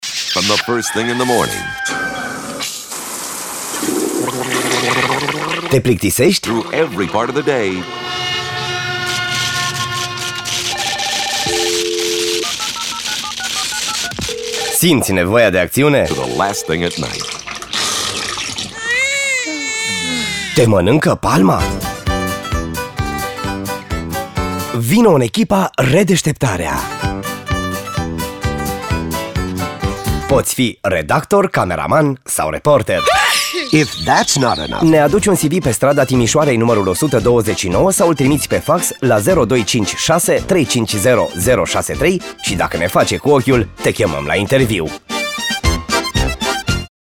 annonce emploi radio en roumain